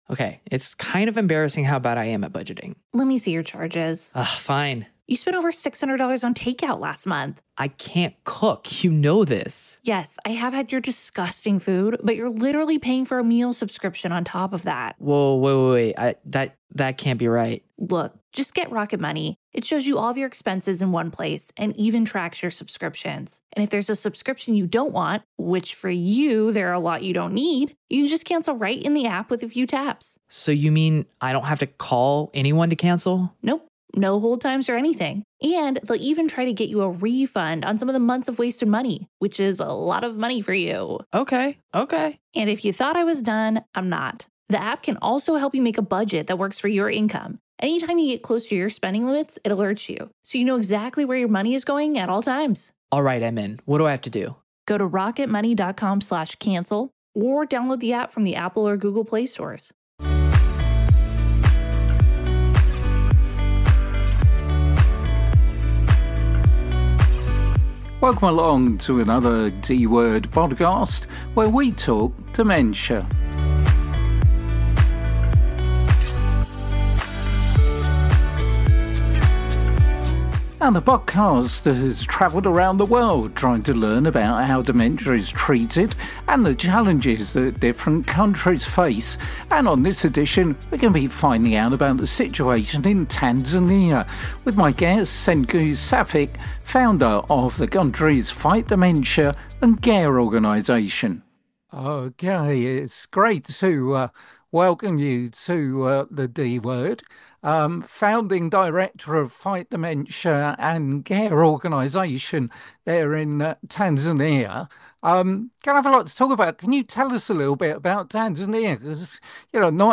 The ‘D’ Word is the UK’s only dementia-focused radio show.